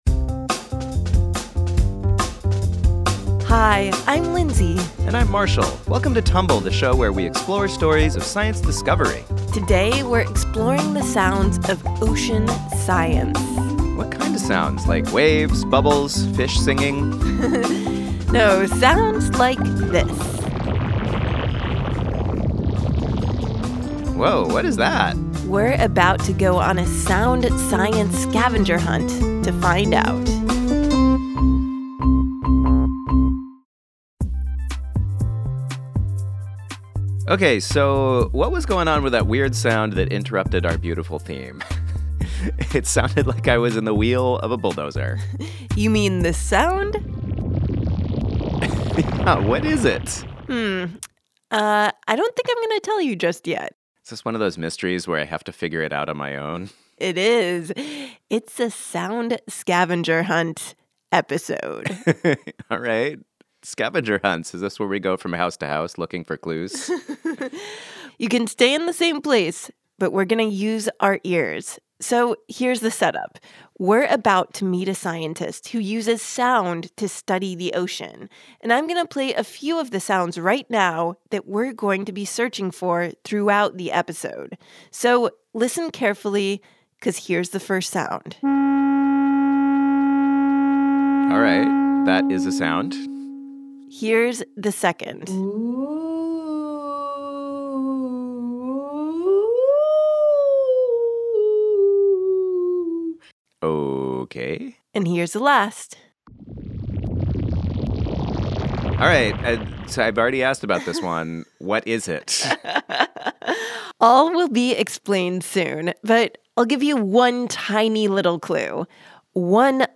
Explore the sounds of ocean science on a sound scavenger hunt!